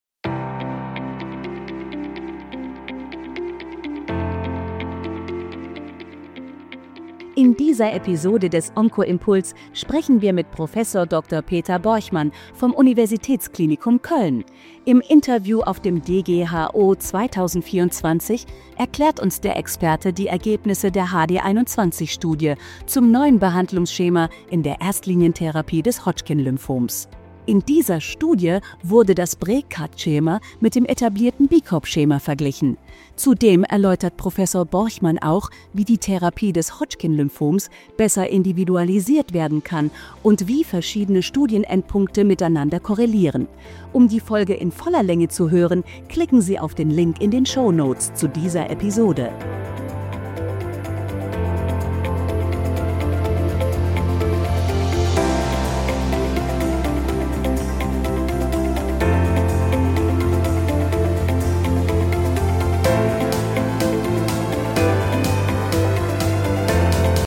Interview zum DGHO 2024 und einem Studienupdate zur Therapie des